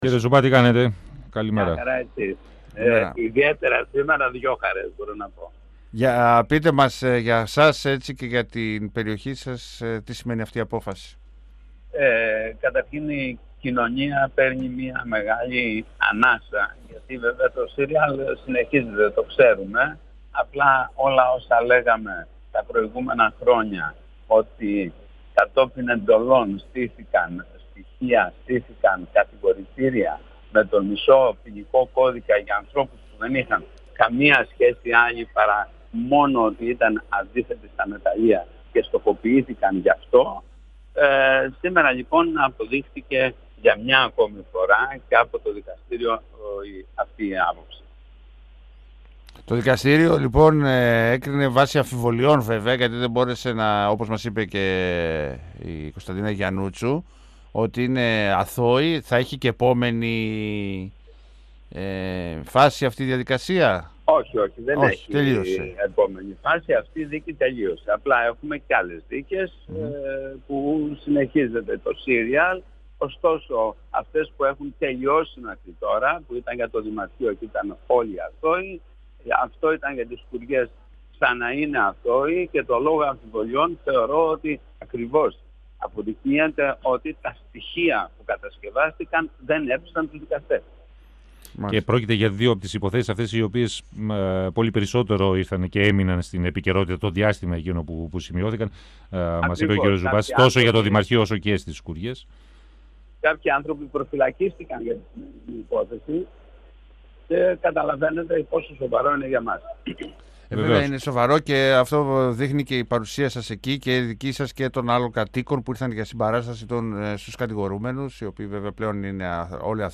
Ο δήμαρχος Αριστοτέλη, Γιώργος Ζουμπάς, στον 102FM του Ρ.Σ.Μ. της ΕΡΤ3